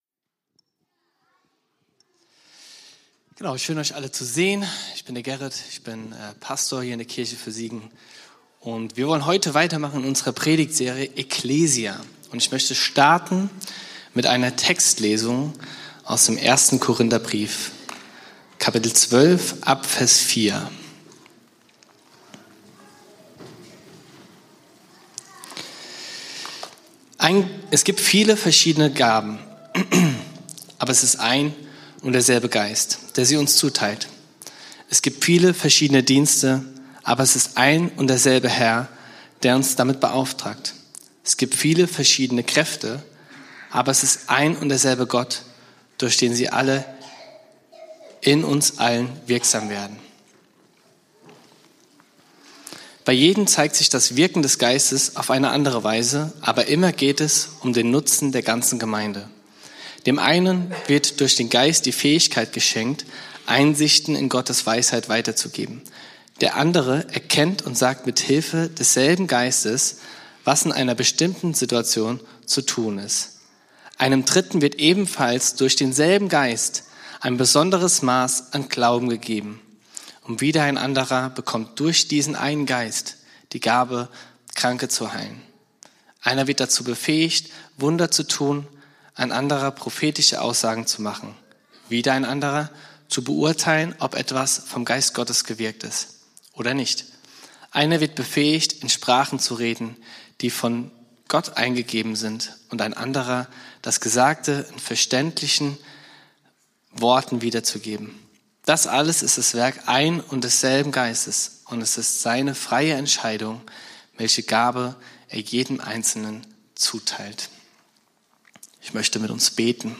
Predigt vom 15.03.2026 in der Kirche für Siegen